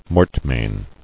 [mort·main]